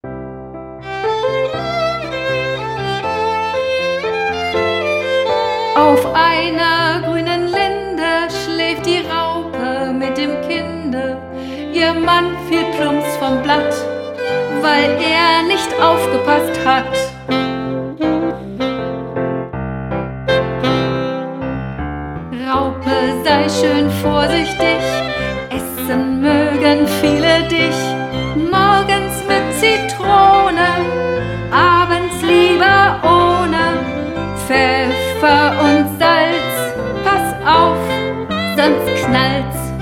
13 pfiffige Lieder mit Schwung